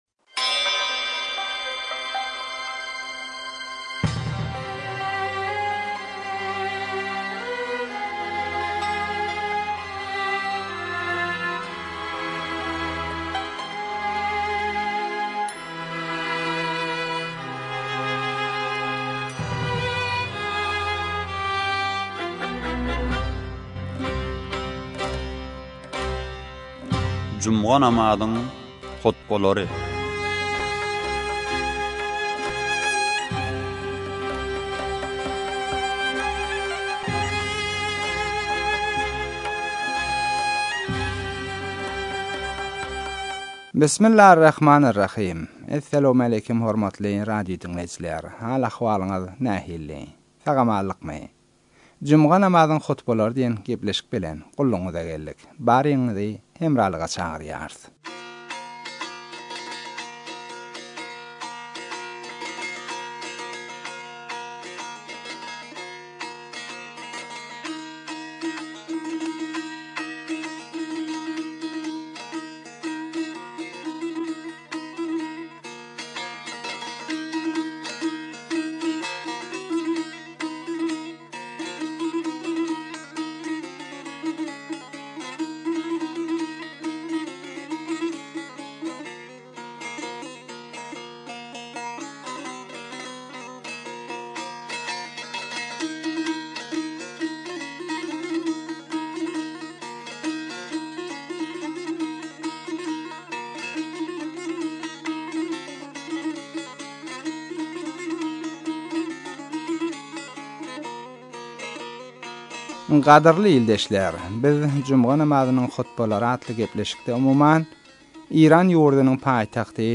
Juma namzynyň hotbalary 7